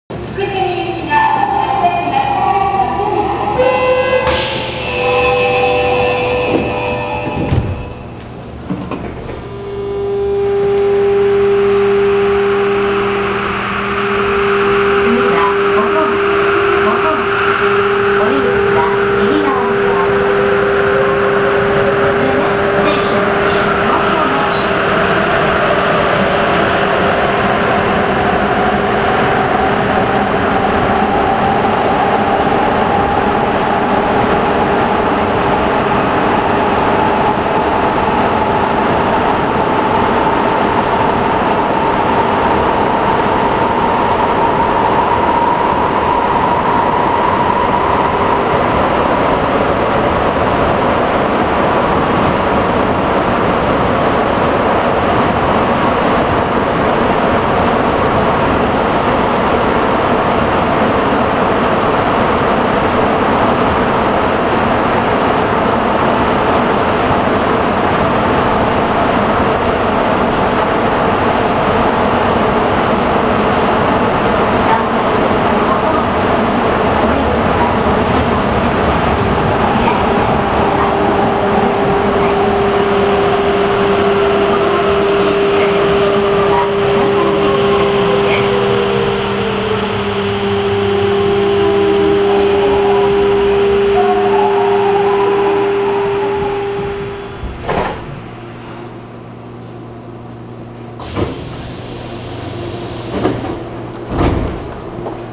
・7000形走行音
【東豊線】新道東〜元町（1分45秒：573KB）…低音がよく響く車両
発車時の低音がよく響く車両とそうではない車両がいるようです。